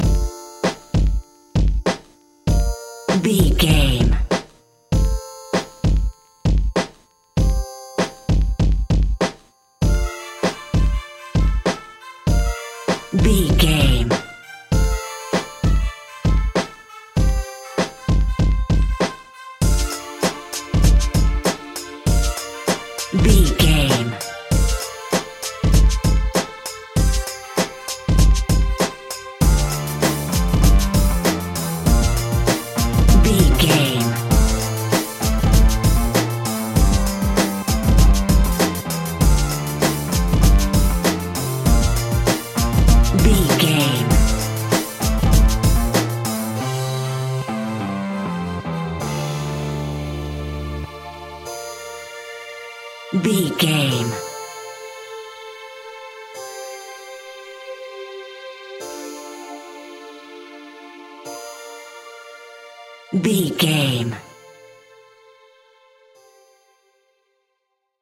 Rap Meets Classical.
Aeolian/Minor
electronic
trance
industrial
synth lead
synth bass